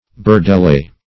Search Result for " burdelais" : The Collaborative International Dictionary of English v.0.48: Burdelais \Bur`de*lais"\, n. [F. bourdelais, prob. fr. bordelais.